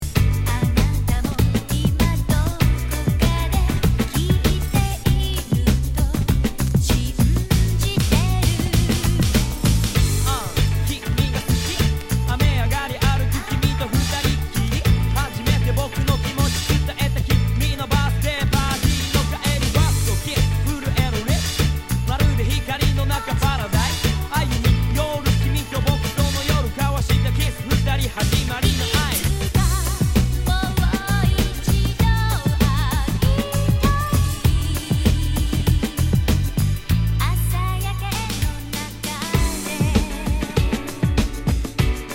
Category       レコード / vinyl 12inch
Tag       Japan R&B